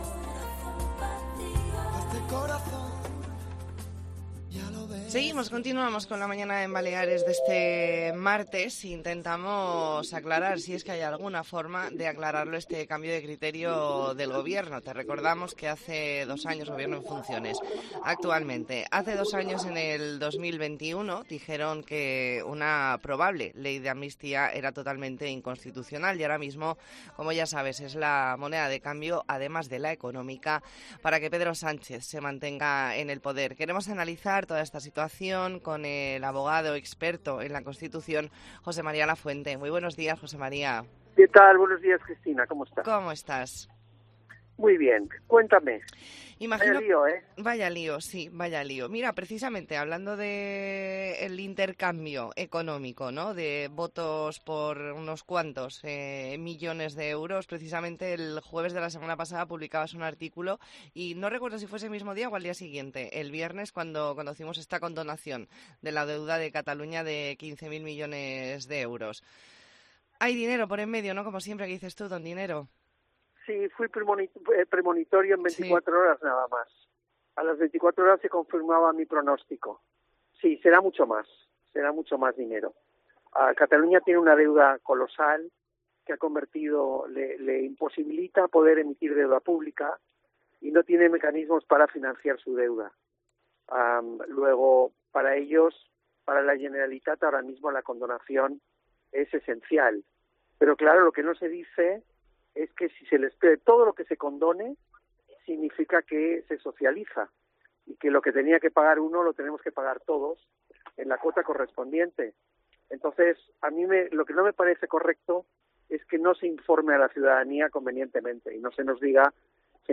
Entrevista en La Mañana en COPE Más Mallorca, martes 7 de noviembre de 2023.